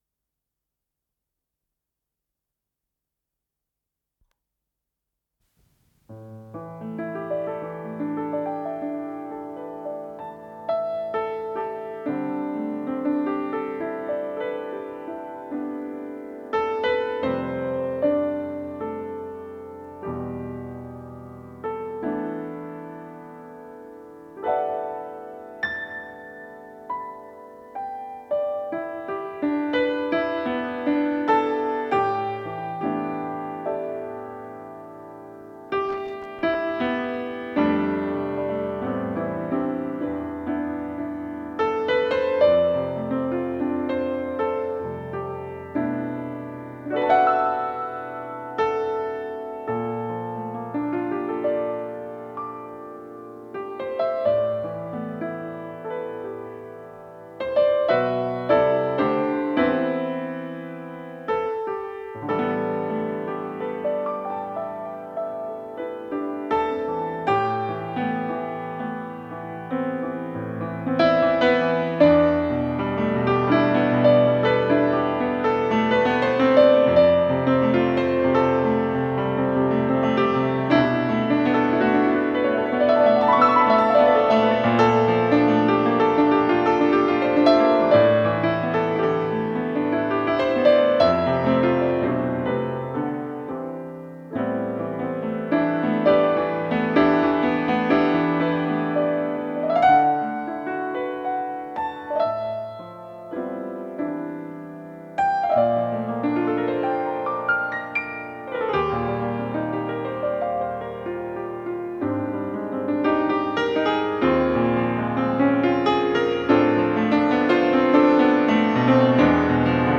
ПодзаголовокПьеса для фортепиано, ля минор
ВариантДубль моно